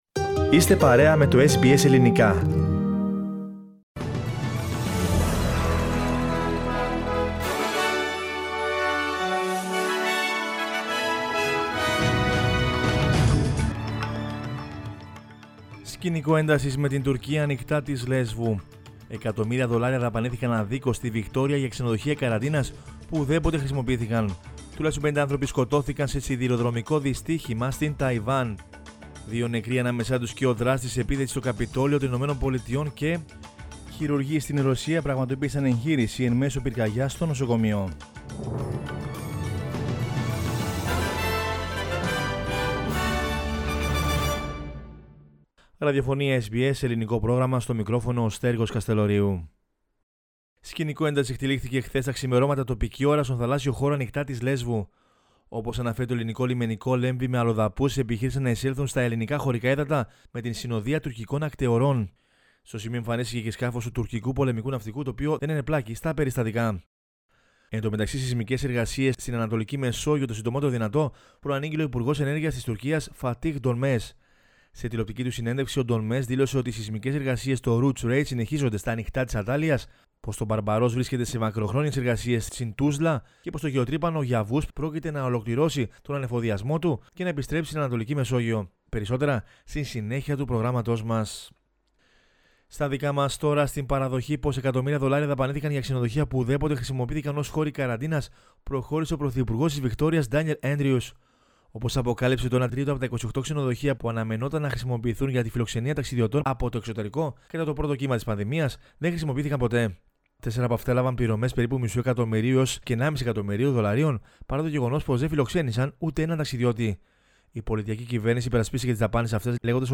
News in Greek from Australia, Greece, Cyprus and the world is the news bulletin of Saturday 3 April 2021.